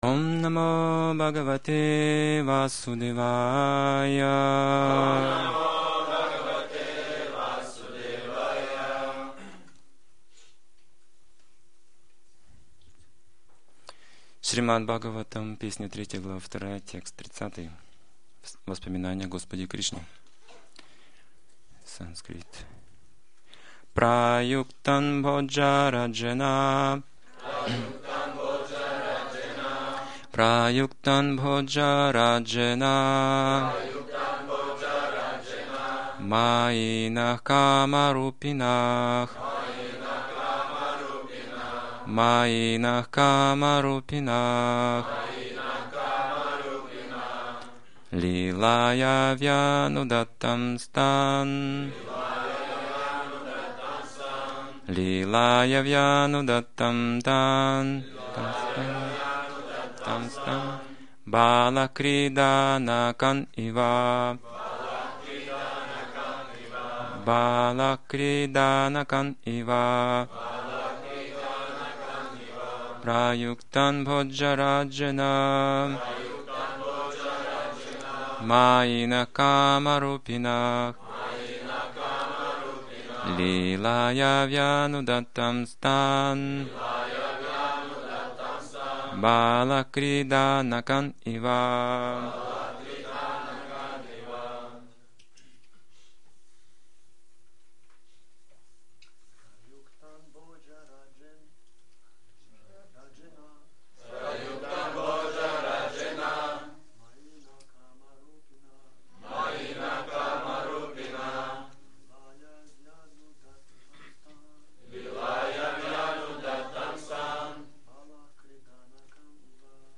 Темы, затронутые в лекции: С помощью преданного служения можно проникнуть в тайну бхакти Преданные привлекаются личностной формой Господа Враждующий с Господом призывает свою смерть Только Гуру знает как сломать ложное эго не разрушая личности Кришна лично защищает преданных Главный аспект Господа - любовь Преданное служение - это путь к Кришне Гуру хочет в этой жизни вернуть ученика к Богу Нужно научиться черпать силы из океана